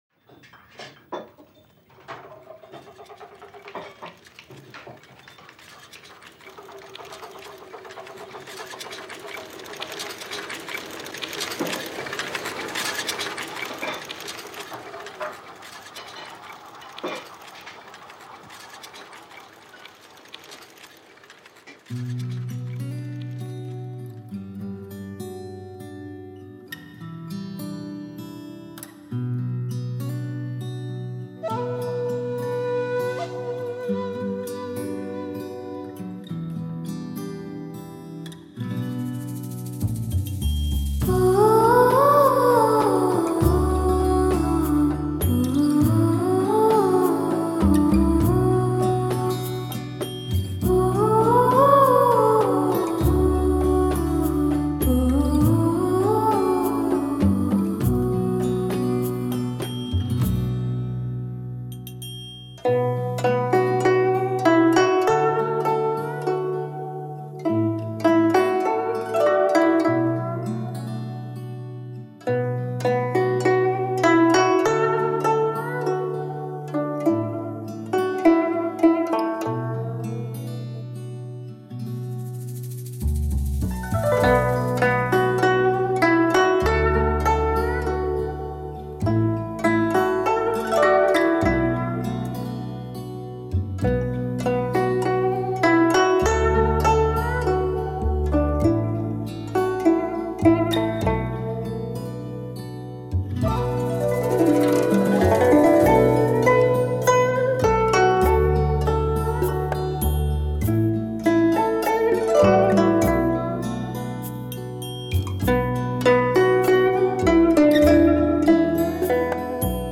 代表性和特殊艺术魅力的古老乐器；它音色清越、高洁、
典雅、委婉动听，乐章华丽，富有神韵。